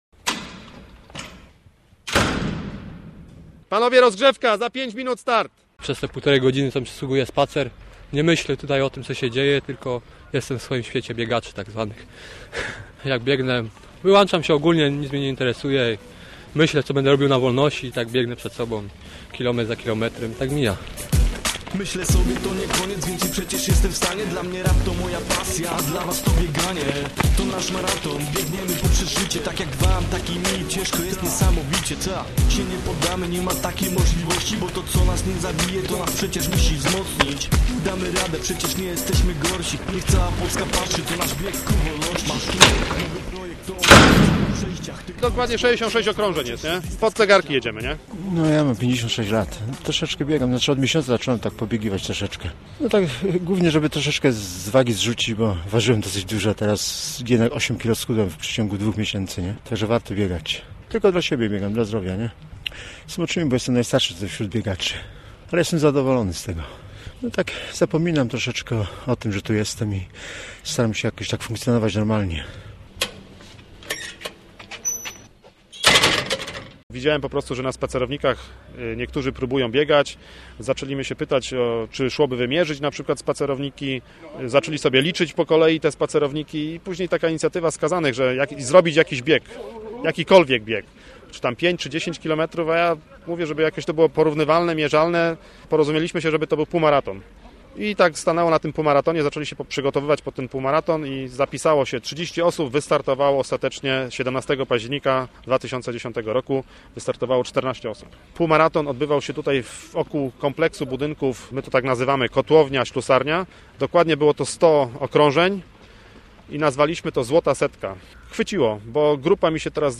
779o4mbftmgc8rn_zlota_setka_reportaz.mp3